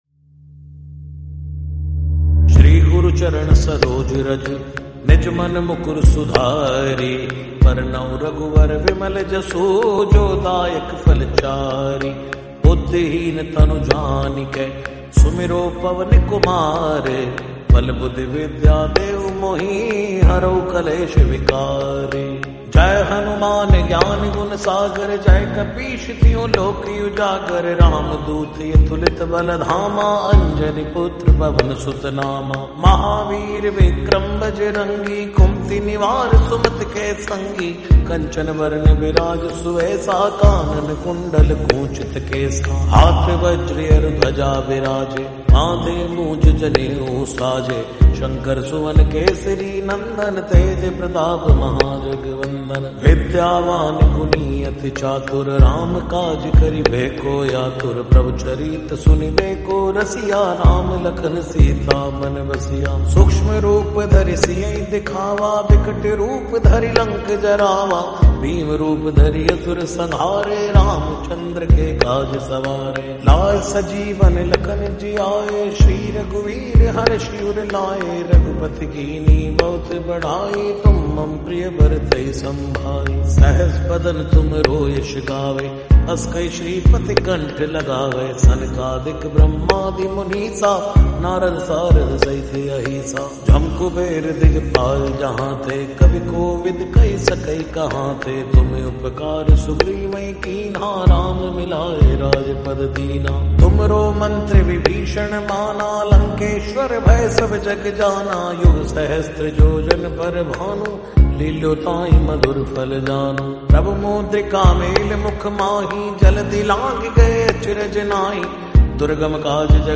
Devotional verses with seamless audio experience.
Hanuman_Chalisa-Lofi.mp3